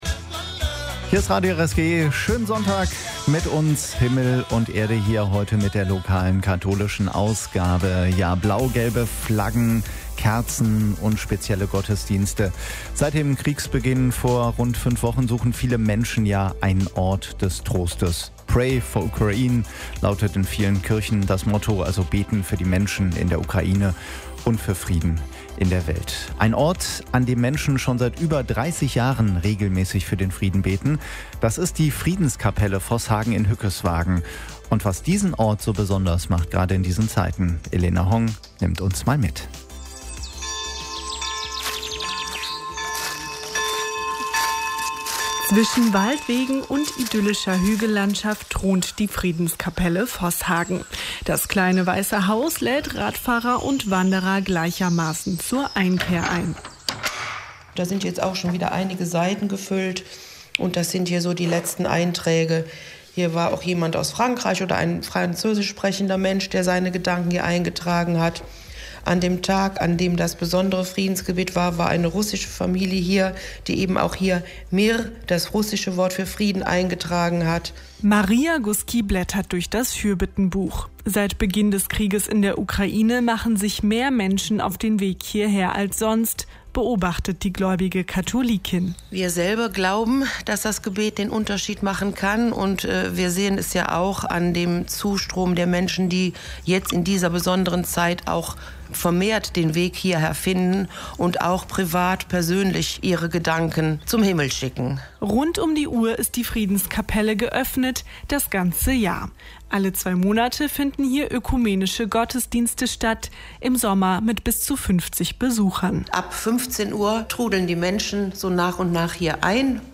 (Bericht vom 27.03.2022)